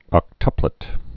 (ŏk-tŭplĭt)